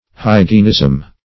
hygienism - definition of hygienism - synonyms, pronunciation, spelling from Free Dictionary Search Result for " hygienism" : The Collaborative International Dictionary of English v.0.48: Hygienism \Hy"gi*en*ism\, n. Hygiene.
hygienism.mp3